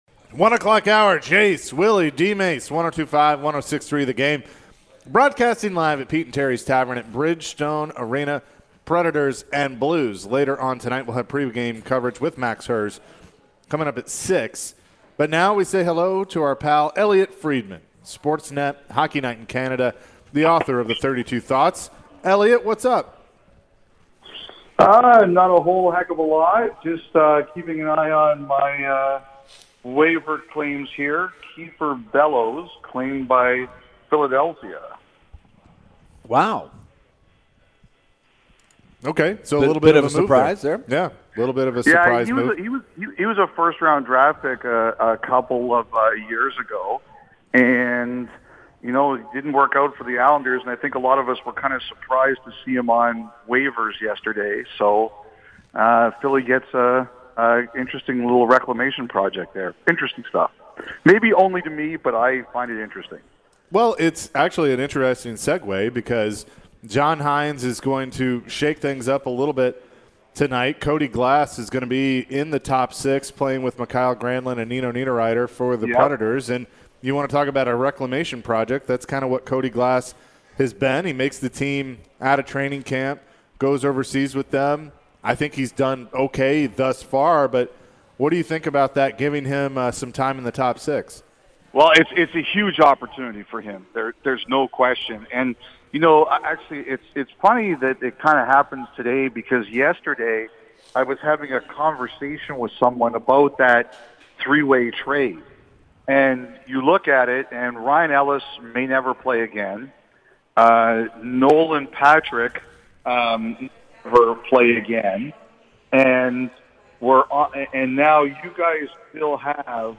Elliotte Friedman Full Interview (10-27-22)